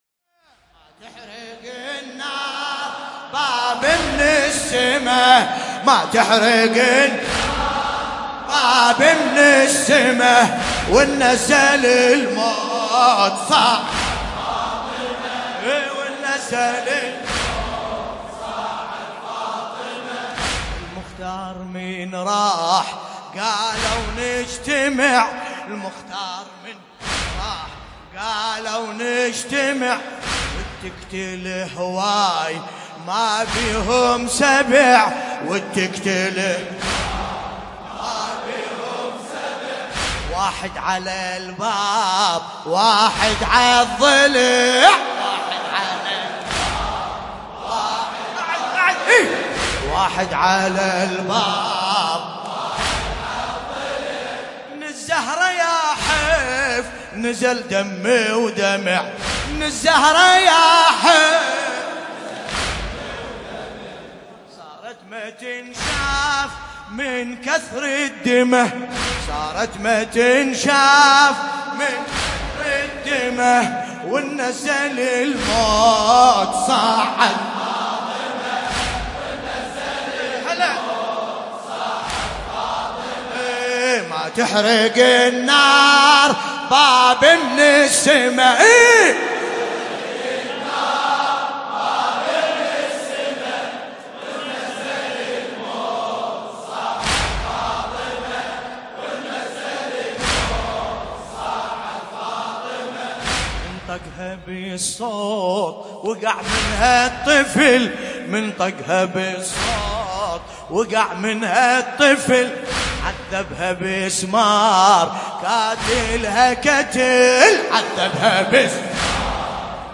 دانلود مداحی شهادت حضرت زهرا (س) ایام فاطمیه جدید شب 9 جمادى الاول 1439 ه.ق ملاباسم کربلایی
سینه زنی
مداحی فاطميه